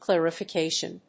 音節clar・i・fi・ca・tion 発音記号・読み方
/kl`ærəfɪkéɪʃən(米国英語), ˌklerʌfʌˈkeɪʃʌn(英国英語)/